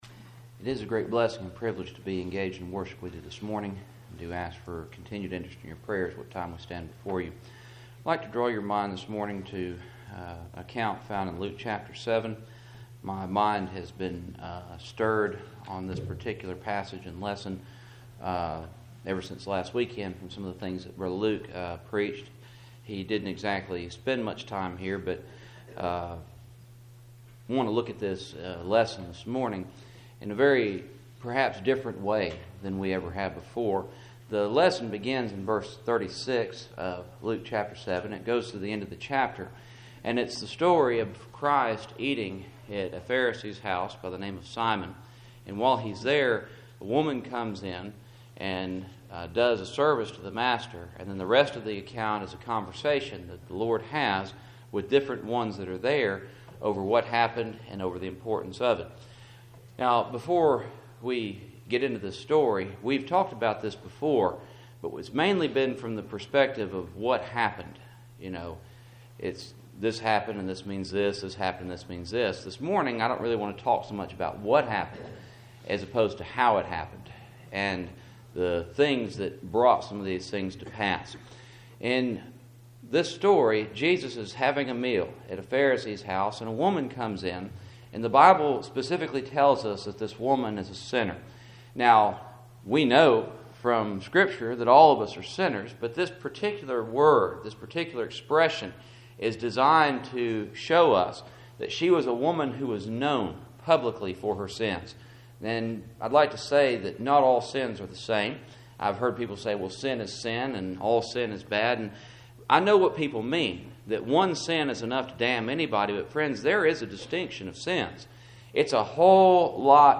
Luke 7:36-50 Service Type: Cool Springs PBC Sunday Morning %todo_render% « Why Do We Wash Feet Leaven